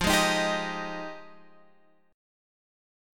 F Minor 9th